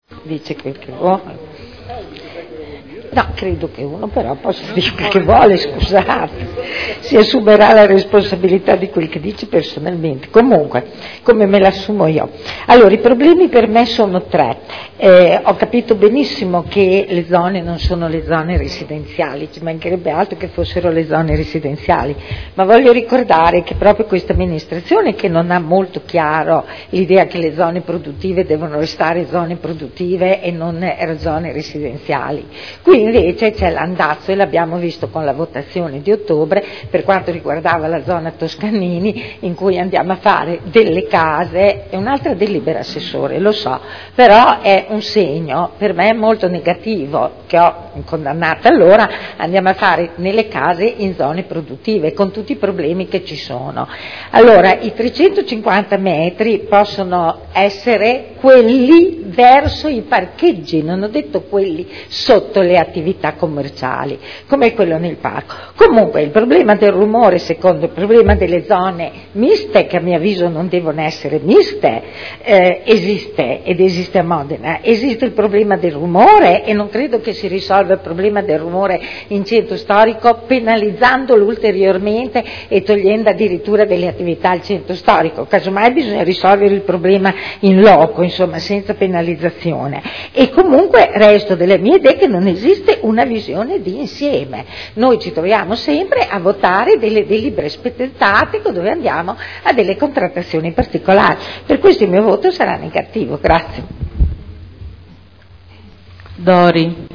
Seduta del 30/05/2011. Dichiarazione di voto su proposta di deliberazione: Variante al POC e al RUE relativamente a pubblici esercizi e merceologie ingombranti, aggiornamento della disciplina degli immobili con codici ISTAR-ATECO 2002 – Approvazione